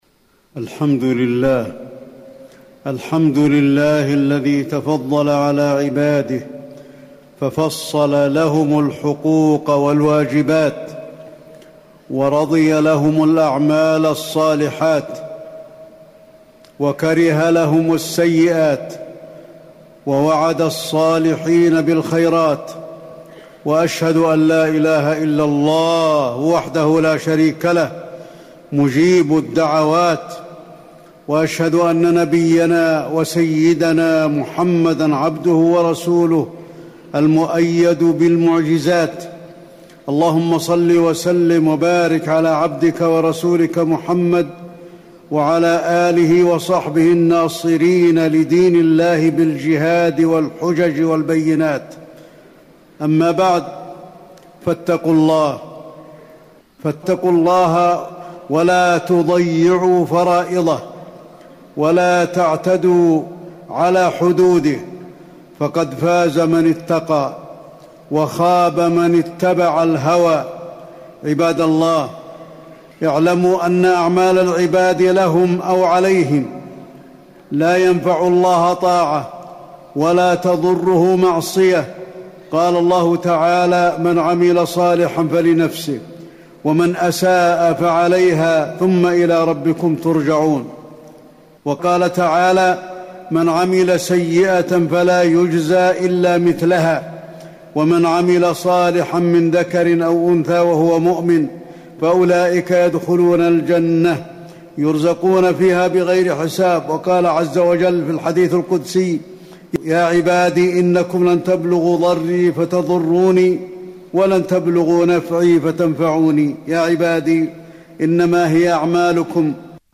تاريخ النشر ٢٠ ذو الحجة ١٤٣٩ هـ المكان: المسجد النبوي الشيخ: فضيلة الشيخ د. علي بن عبدالرحمن الحذيفي فضيلة الشيخ د. علي بن عبدالرحمن الحذيفي حقوق الوالدين